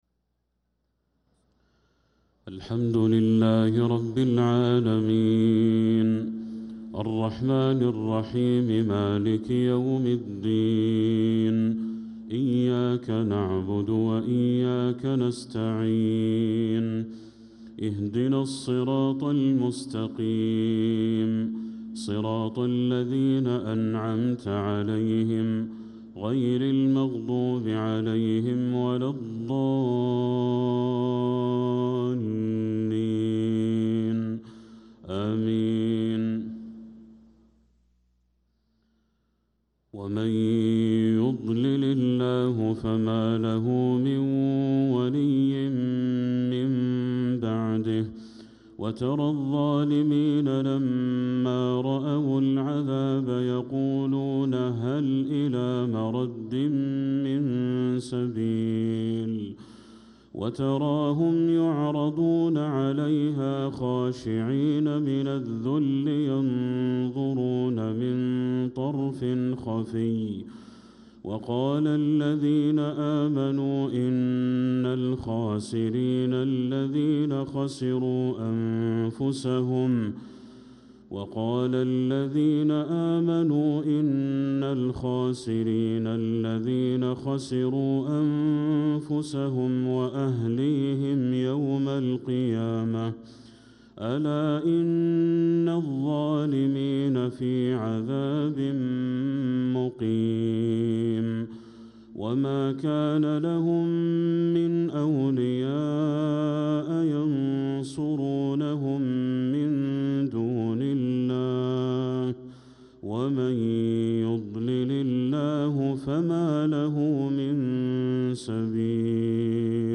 صلاة العشاء للقارئ بدر التركي 21 جمادي الأول 1446 هـ